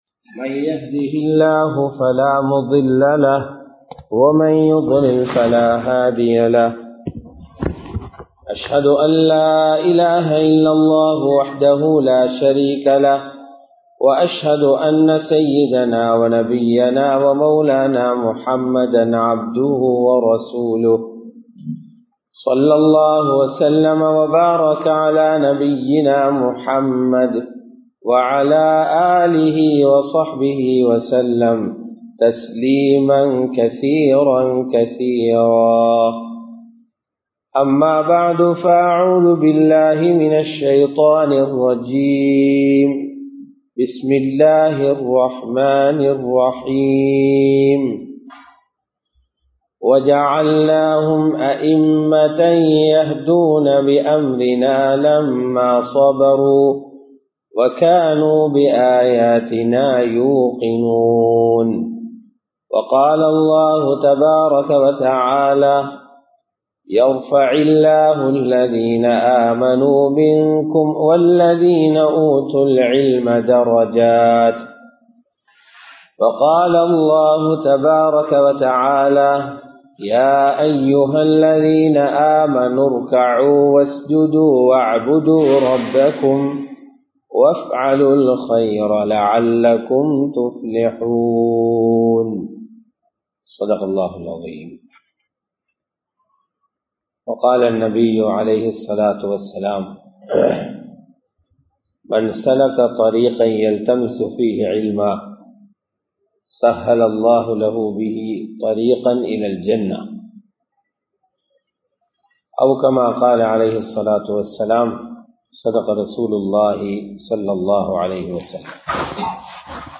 அல்லாஹ்வை உறுதியாக நம்பும்வோம் | Audio Bayans | All Ceylon Muslim Youth Community | Addalaichenai